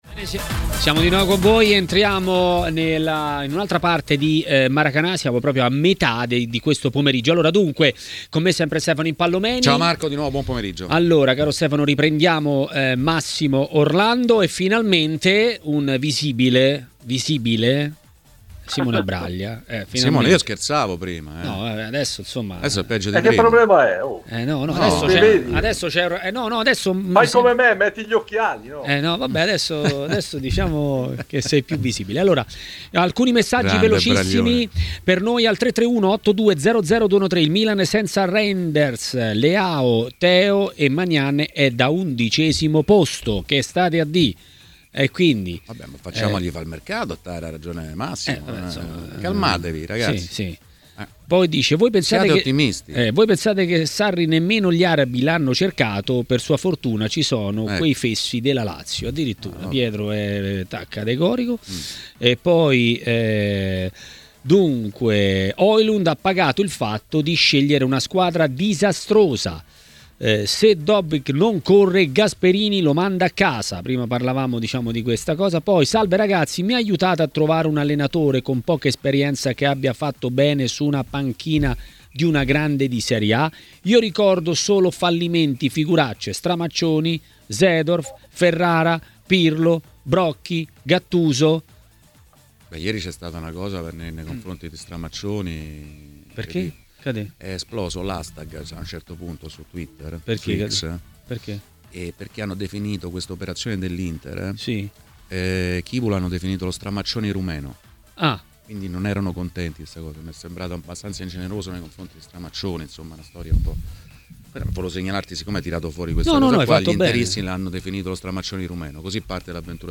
intervistato da TMW Radio